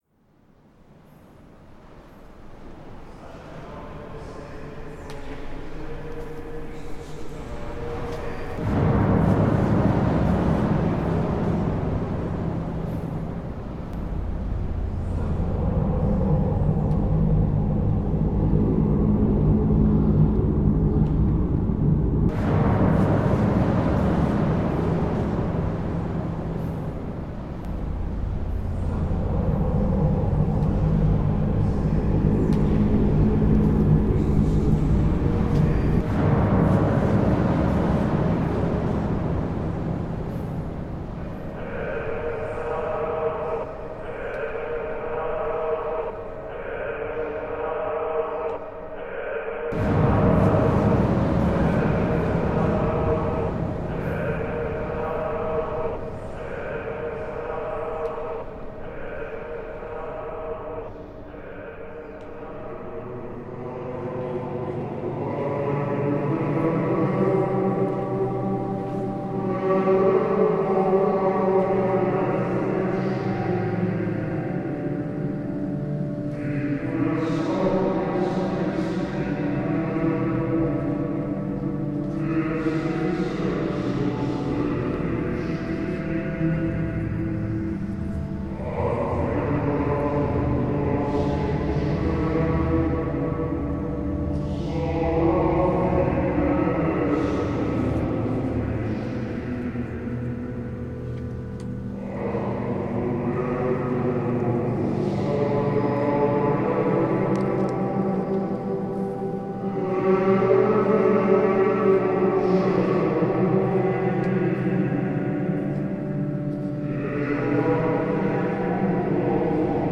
Santa Maria delle Grazie in Rome reimagined